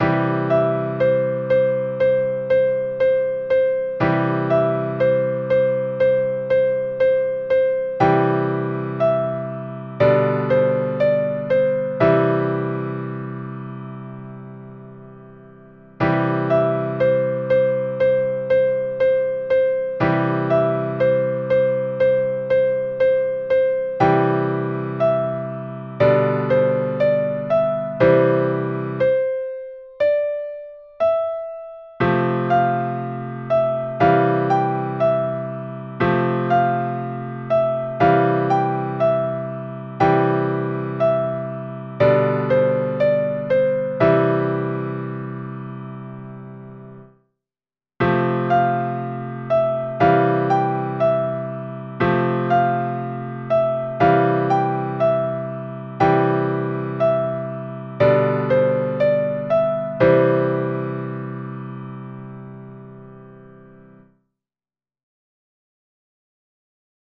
Jewish Folk Song
Piano Arrangement
C major
niggun_020a_mishenichnas_adar_piano_01.mp3